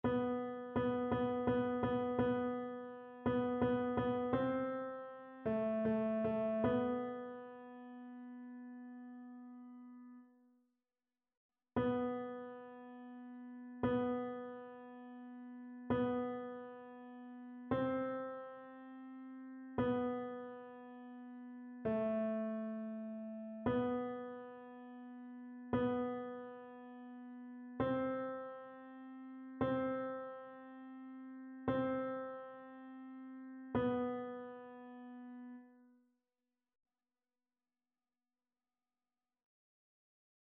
annee-c-temps-ordinaire-23e-dimanche-psaume-89-tenor.mp3